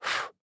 blow_candle.wav